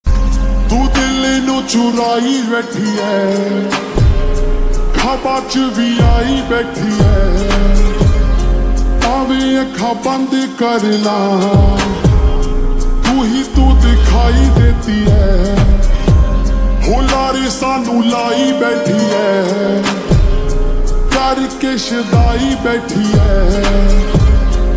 Hindi Songs
(Slowed + Reverb)